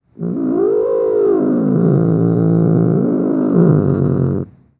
3 - Challenge : Faire râler le chat
Miaulement2.wav